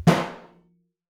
timpsnaretenor_ff.wav